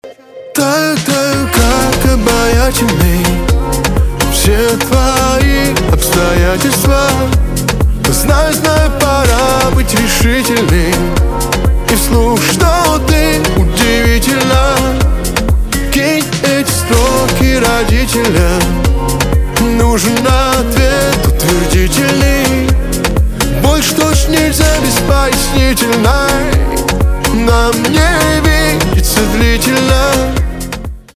поп
романтические , чувственные , битовые , гитара